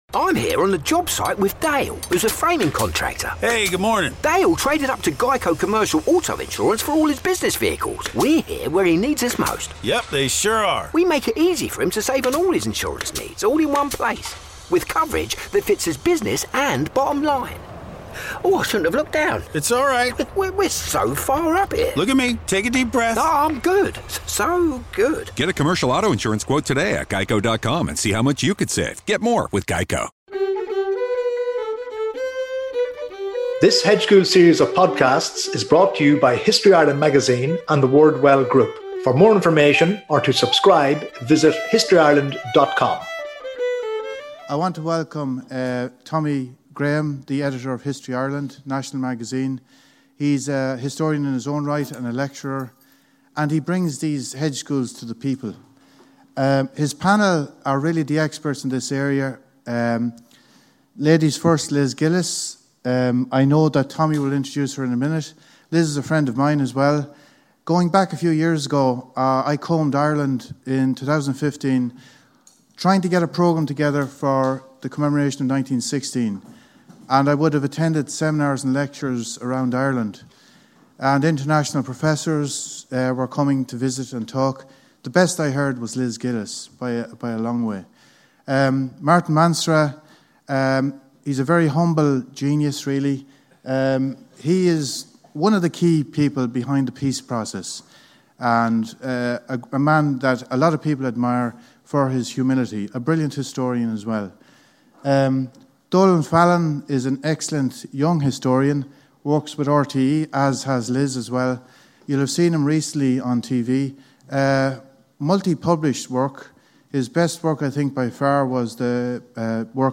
Recorded @ Malahide Community School 2pm Thursday 19 September 2019 A century on—how do we view the War of Independence?